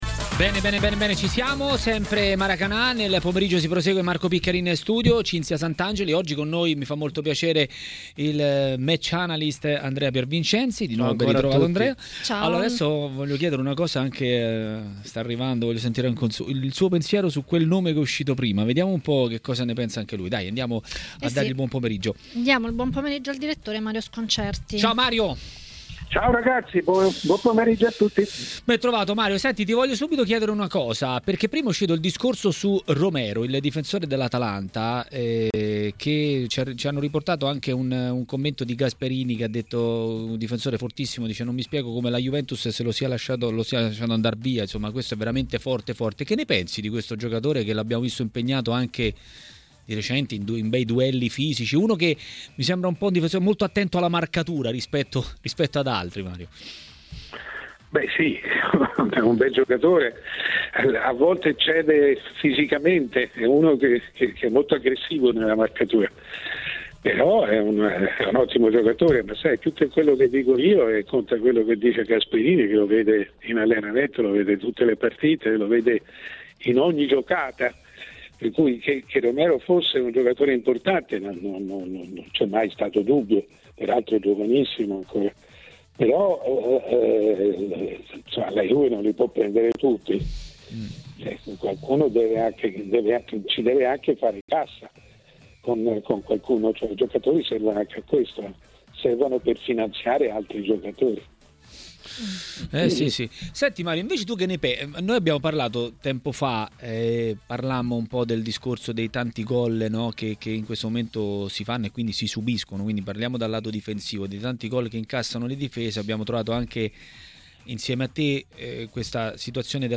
Per parlare di Serie A e di mercato a TMW Radio, durante Maracanà, è intervenuto il direttore Mario Sconcerti.